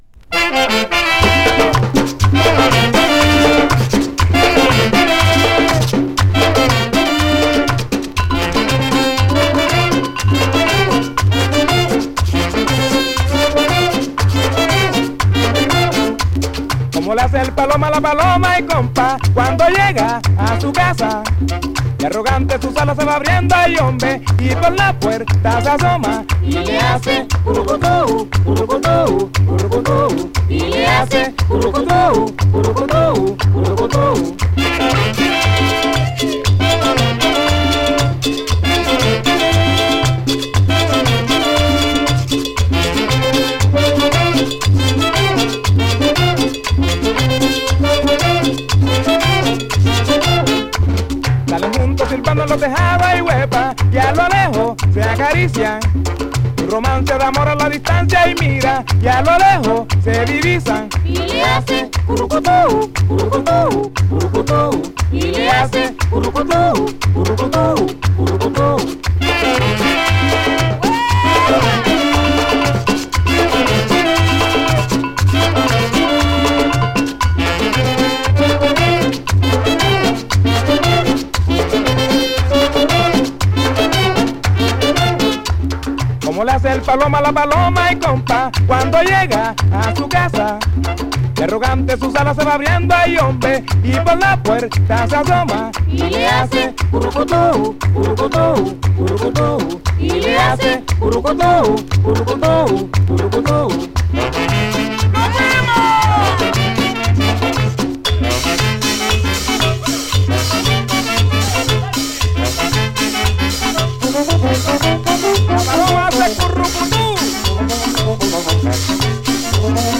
Big Band Cumbia